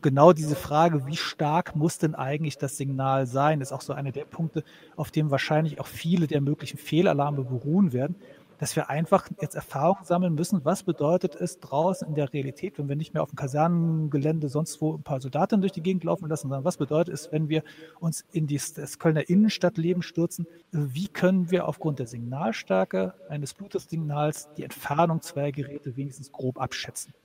O-Töne + Magazin: Die Corona-Tracing-App startet
Im Rahmen der Podiumsdiskussion wurde über die Möglichkeiten und Gefahren der digitalen Verfolgung von Infektionswegen durch die App gesprochen.